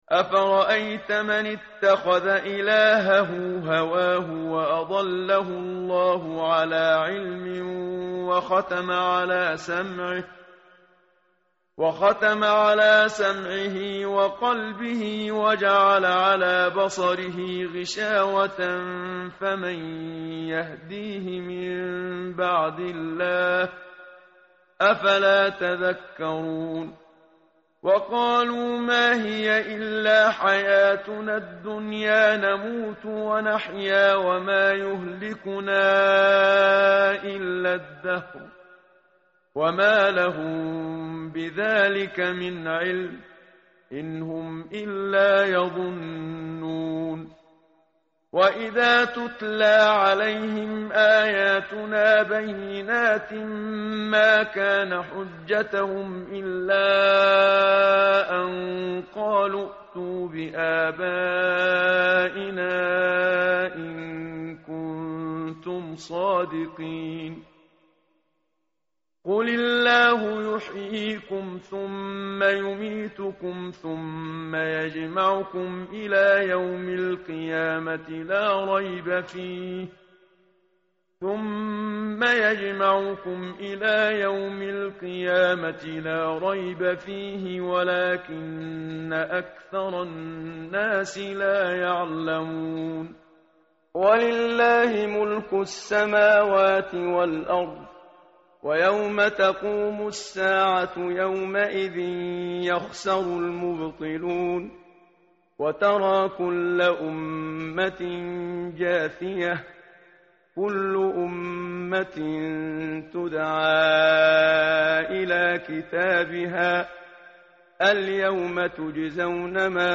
متن قرآن همراه باتلاوت قرآن و ترجمه
tartil_menshavi_page_501.mp3